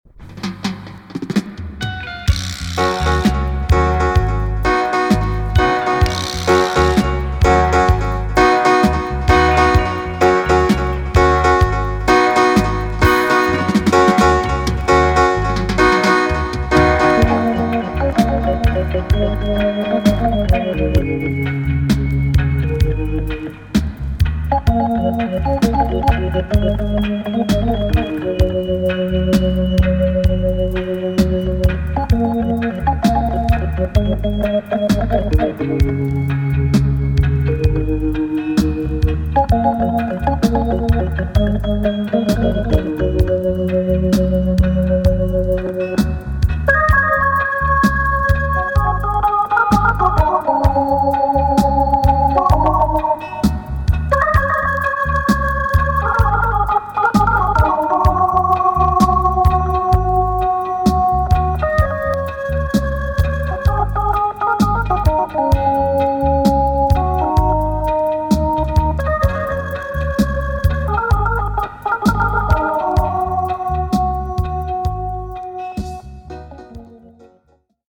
EX 音はキレイです。